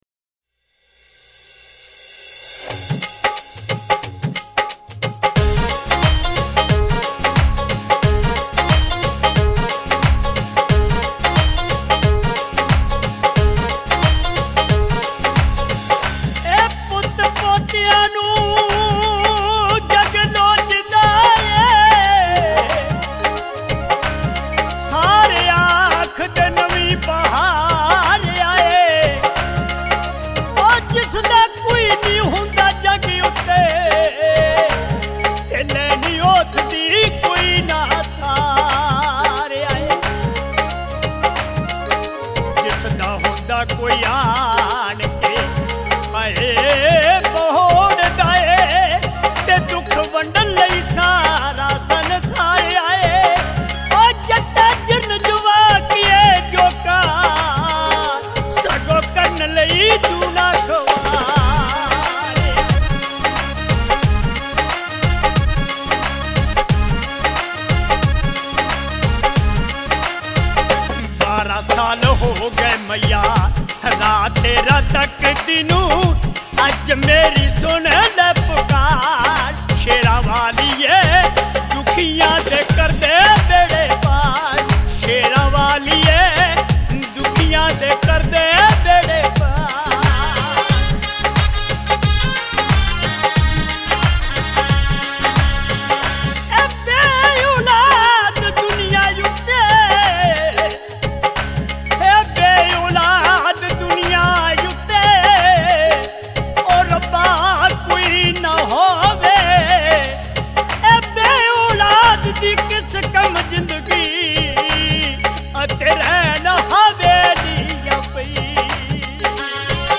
Devotional (Bhajan)